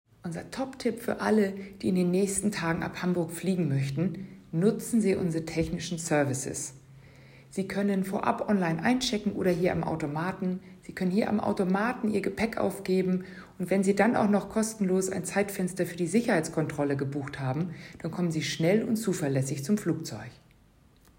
O-Töne